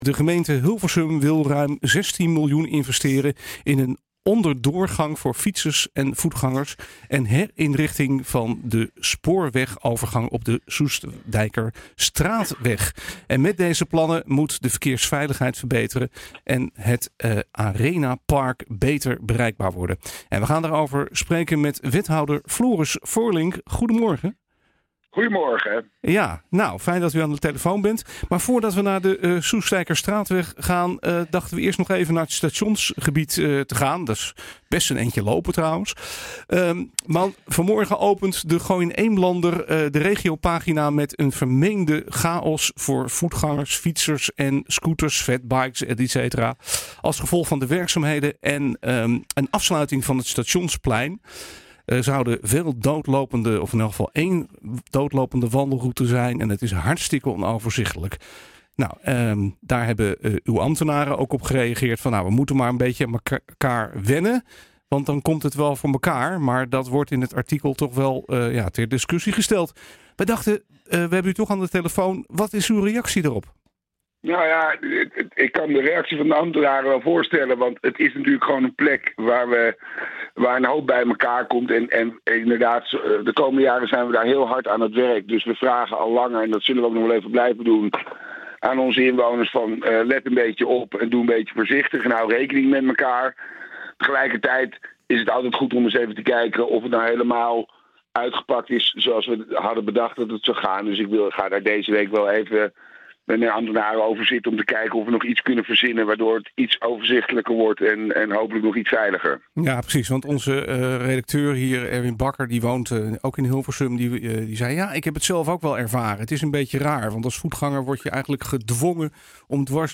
De gemeente Hilversum wil ruim 16 miljoen euro investeren in een onderdoorgang voor fietsers en voetgangers en een herinrichting van de spoorwegovergang op de Soestdijkerstraatweg. Met deze plannen moet de verkeersveiligheid verbeteren en het Arenapark beter bereikbaar worden. Wij spreken met wethouder Floris Voorink.